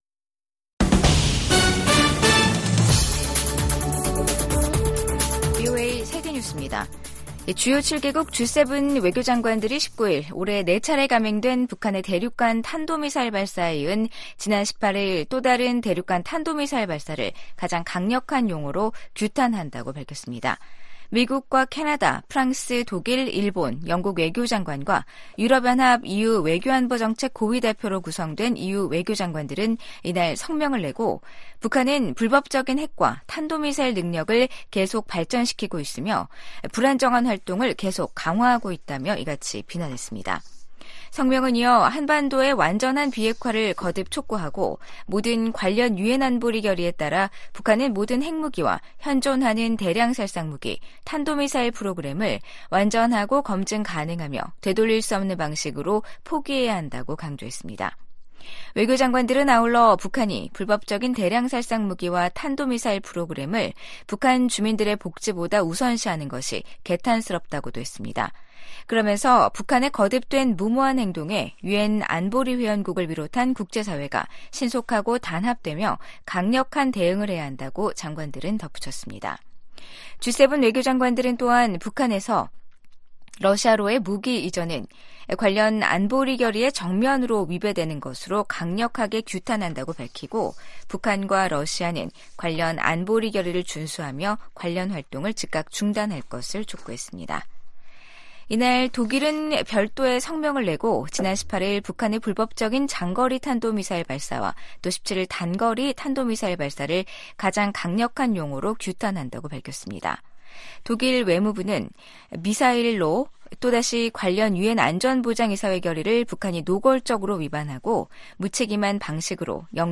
VOA 한국어 간판 뉴스 프로그램 '뉴스 투데이', 2023년 12월 19 일 3부 방송입니다. 유엔 안보리가 북한의 대륙간탄도미사일(ICBM) 발사에 대응한 긴급 공개회의를 개최합니다. 미 국무부는 중국에 북한의 개발 핵 야욕을 억제하도록 건설적 역할을 촉구했습니다. 북한은 어제(18일) 고체연료 기반의 대륙간탄도미사일 ‘화성-18형’ 발사 훈련을 실시했다며 미국 본토에 대한 핵 위협을 노골화했습니다.